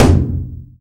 taiko-normal-hitnormal.ogg